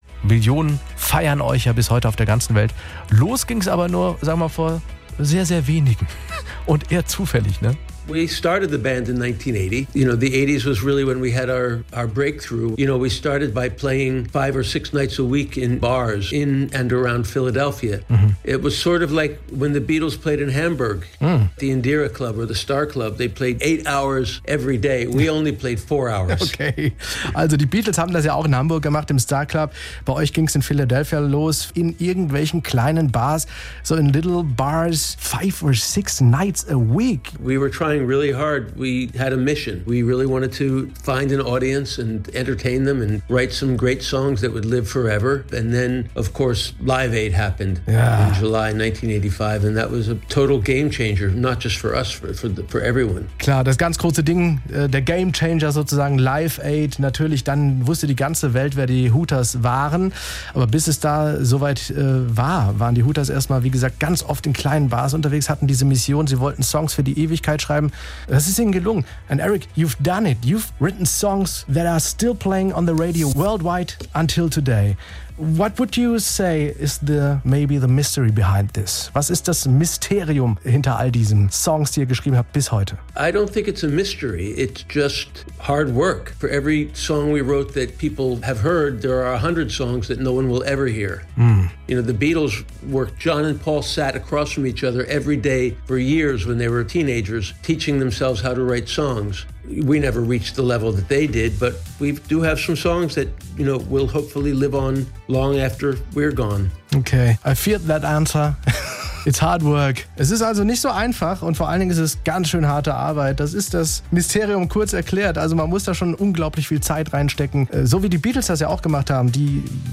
Für die Hooters war die Einladung zum Live Aid Konzert in Philadelphia 1985 ein echter "Gamechanger", erzählt Sänger Eric Bazilian im Interview.